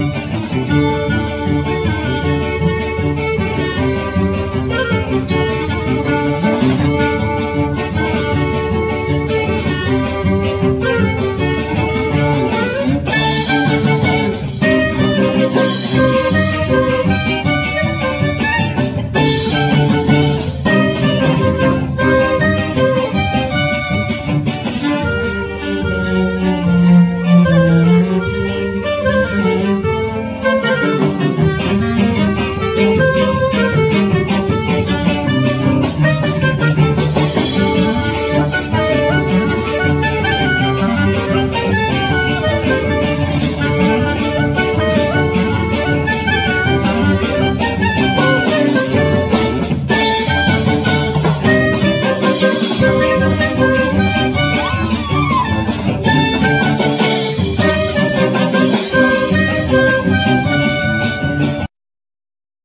Clarinett
Violin
Elctric guitar
Cello
Percussions
Accordian